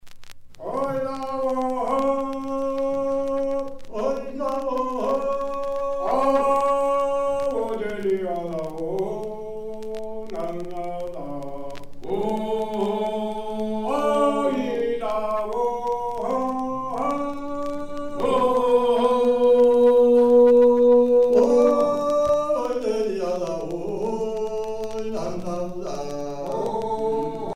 Chants de travail
Pièce musicale éditée